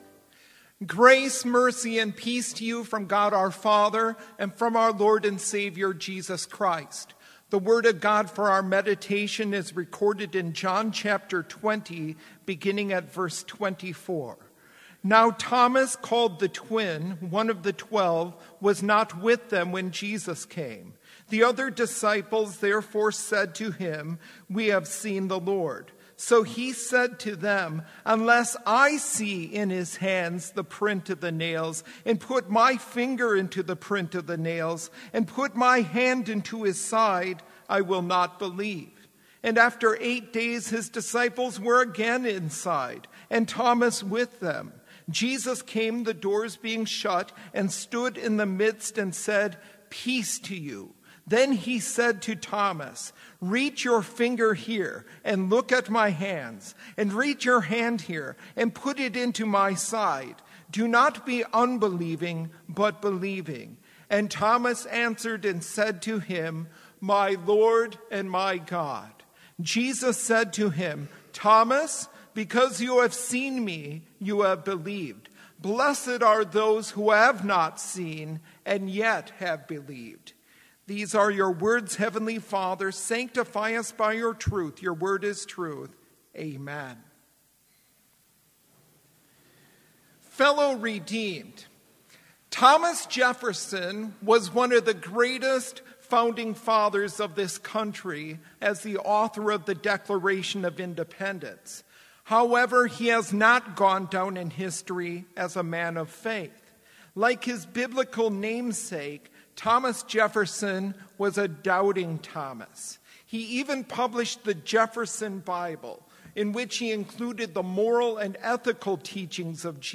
Complete service audio for Chapel - April 30, 2019